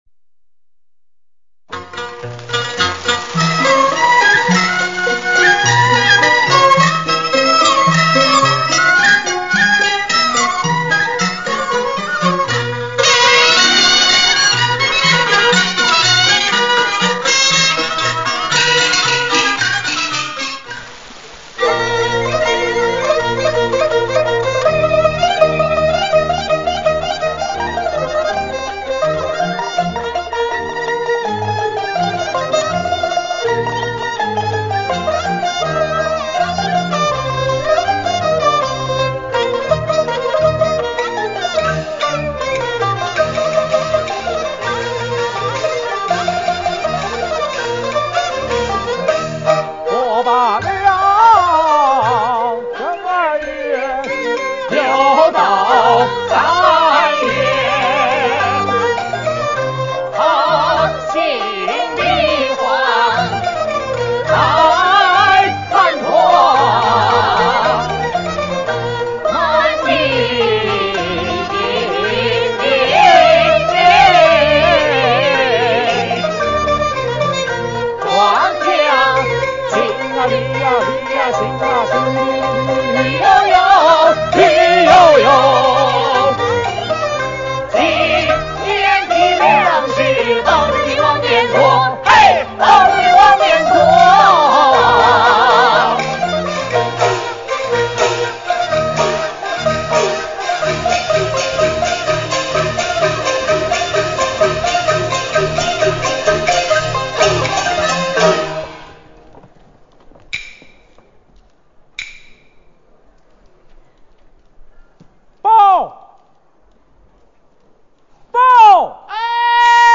司鼓